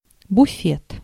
Ääntäminen
IPA: [pla.kaʁ]